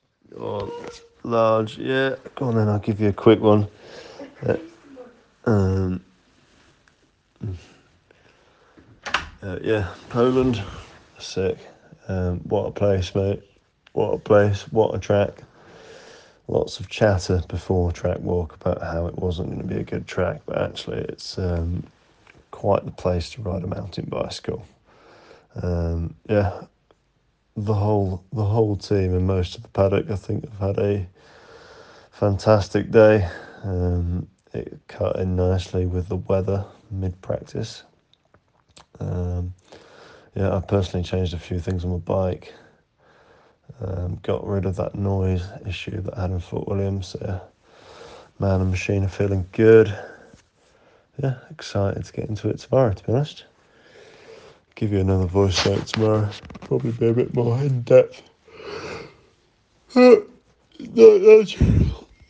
voice note (Vaguely edited)